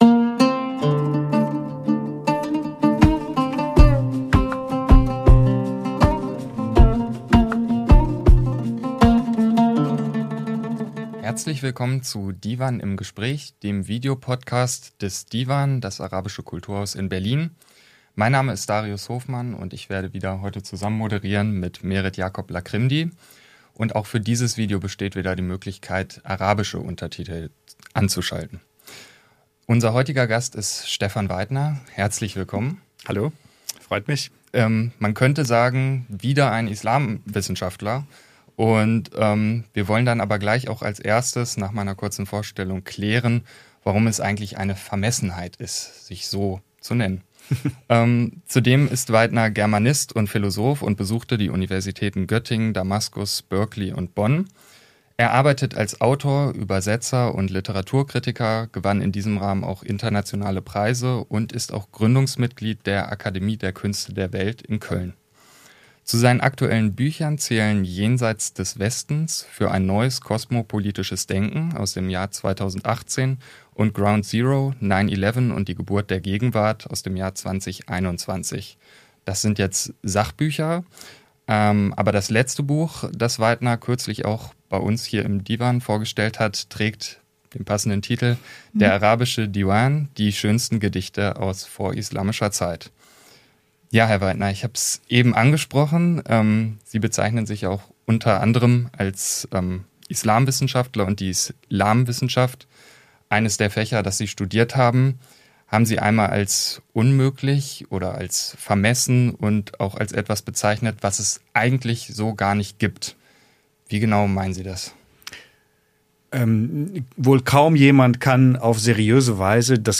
[DE] Im Gespräch mit Stefan Weidner حوار الديوان مع شتيفان فايدنر ~ DIVAN Podcasts Podcast